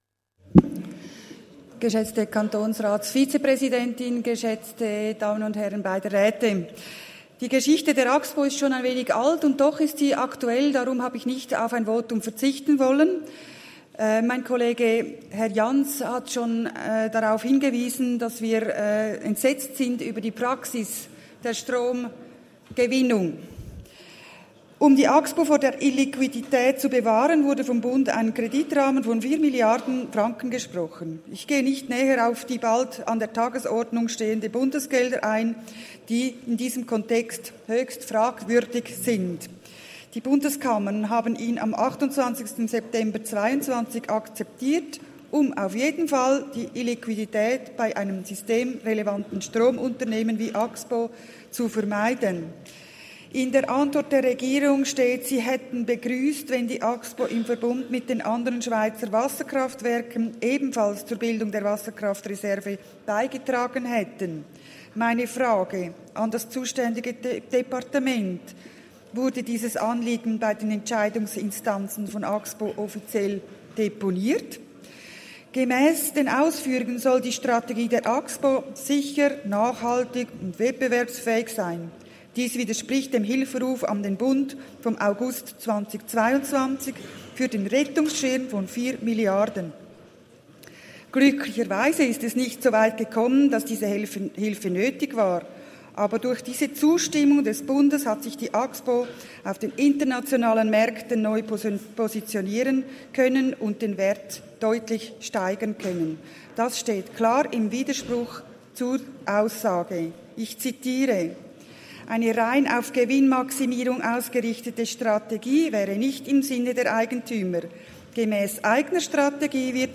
20.9.2023Wortmeldung
Session des Kantonsrates vom 18. bis 20. September 2023, Herbstsession